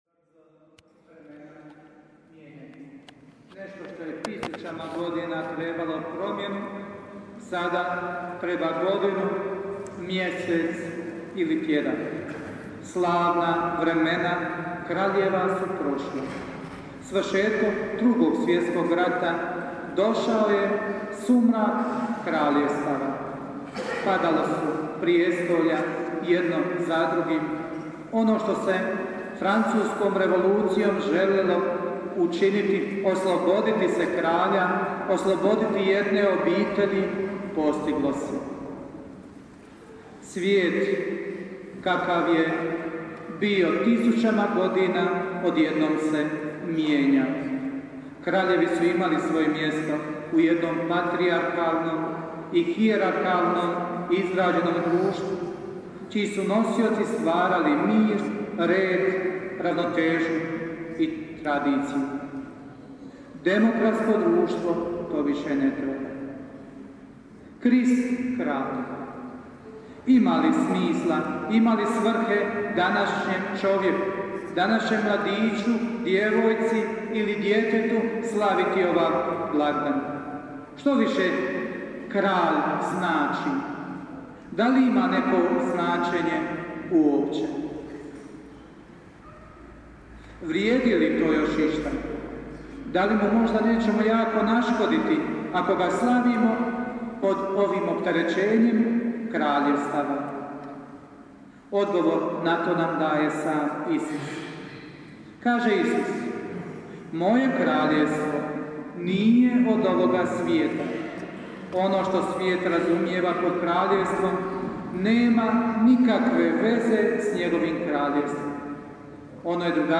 PROPOVJED:
2PROPOVJED_KRISTKRALJ2015.mp3